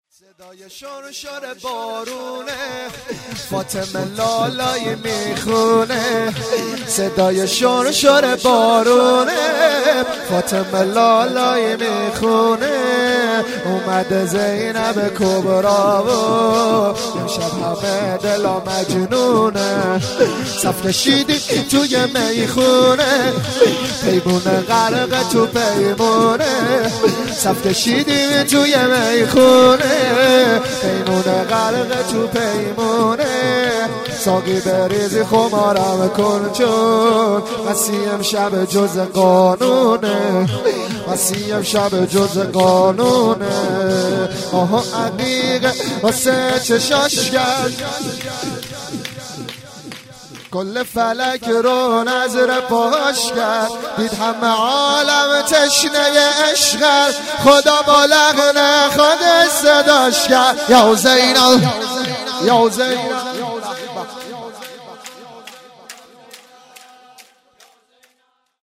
شور - صدای شُر شُرِ بارونه
جشن ولادت حضرت زینب(س)- جمعه 29 دیماه